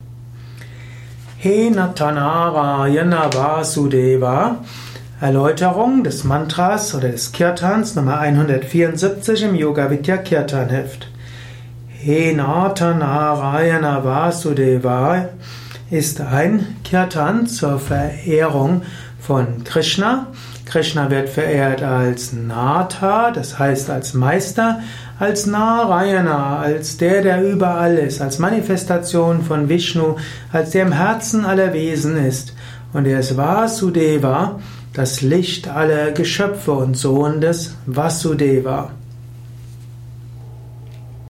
Audio mp3 Erläuterungen